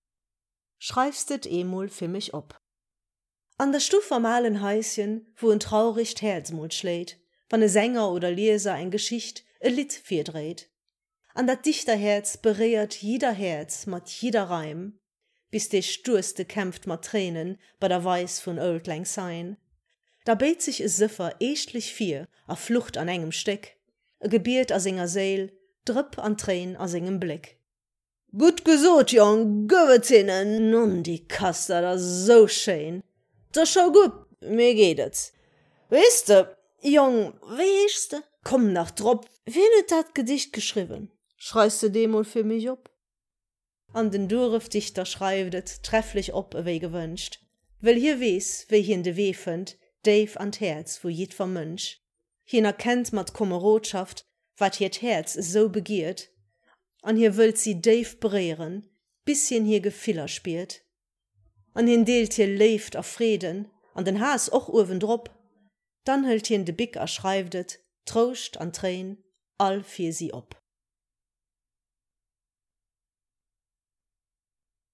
geschwate Versioun.